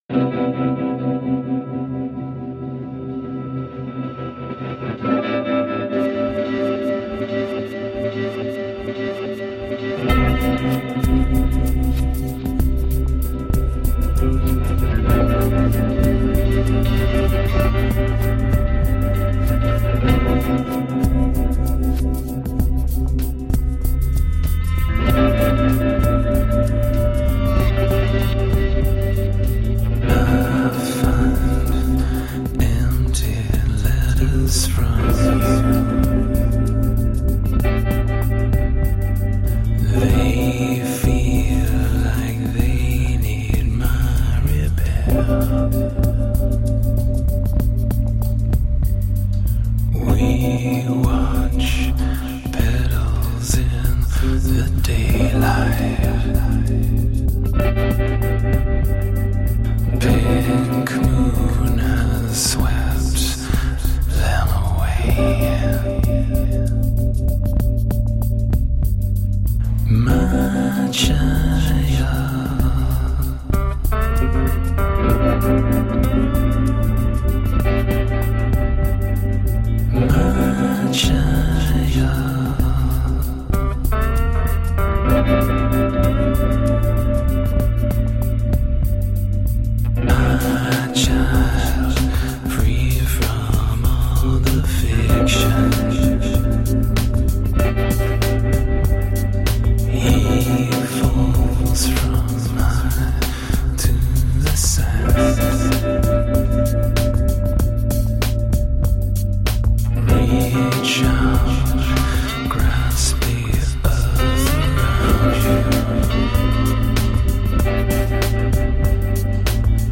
Downtempo groovy electro-pop.